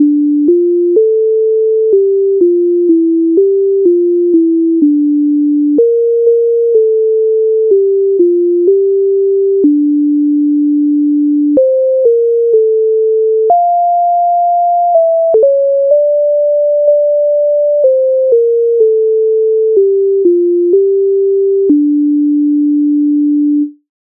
MIDI файл завантажено в тональності d-moll